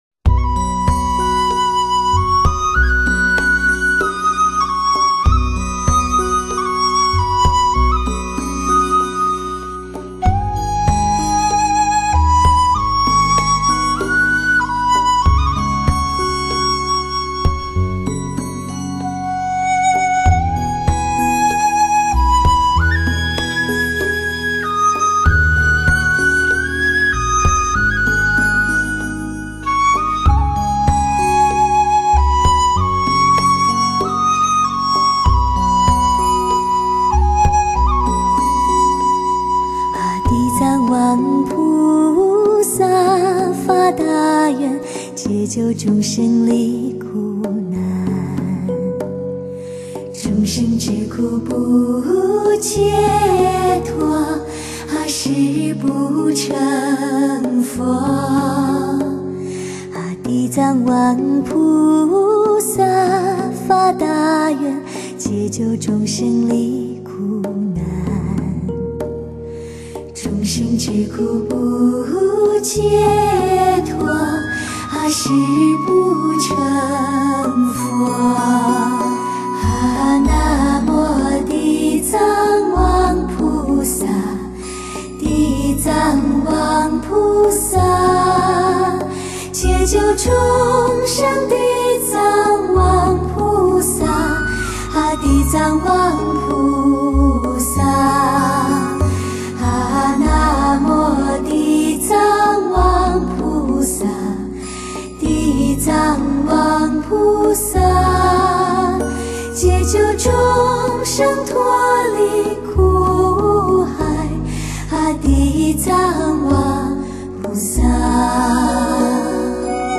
轻柔的合唱音色，从角落里缓缓流淌而出；每一个音符，在空气里细柔地安抚心律；
生动而不失其恬静，像是来自世外桃源的美妙声音，旋起冥想的生命之舞，形成巨大听觉震撼，